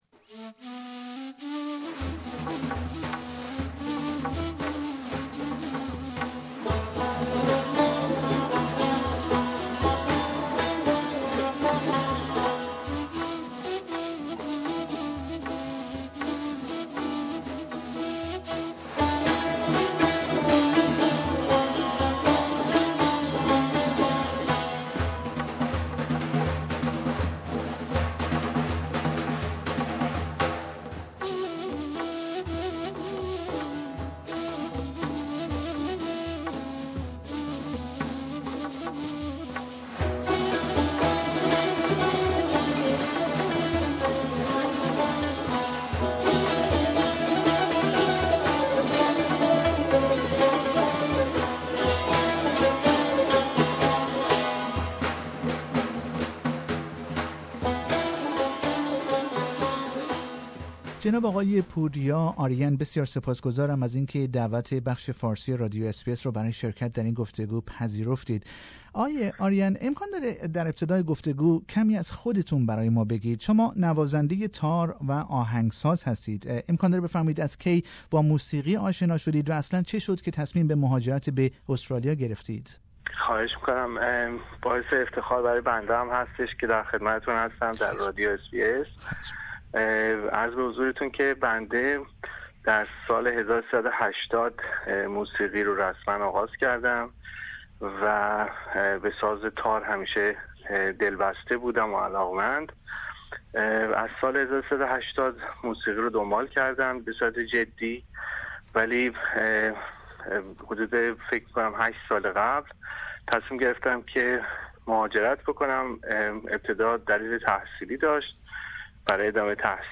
در گفتگو با